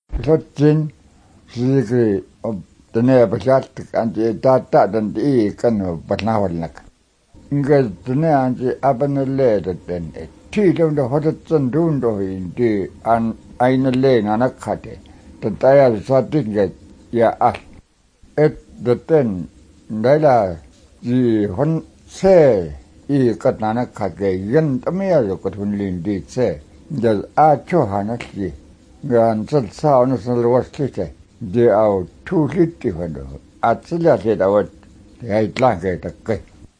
No ejectives or uvular sounds as far as I can tell, but plenty of lateral fricatives.